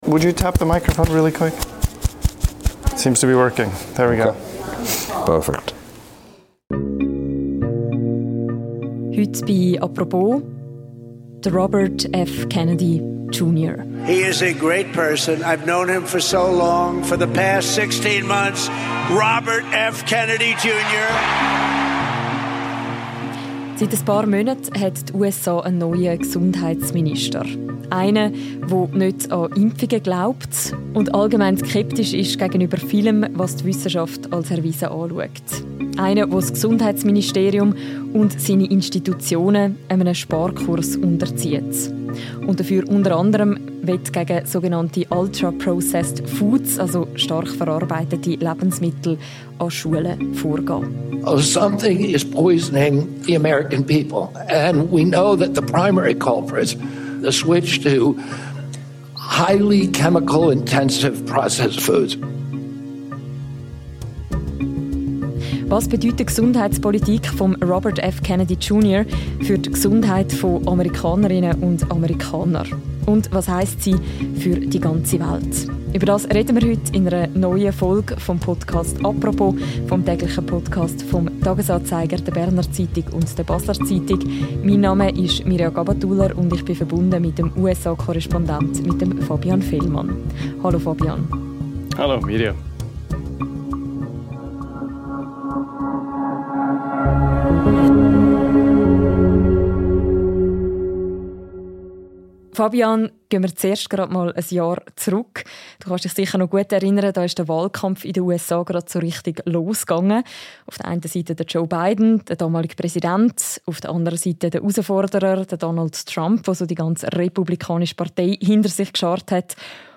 Er ist zu Gast in einer neuen Folge des täglichen Podcasts «Apropos».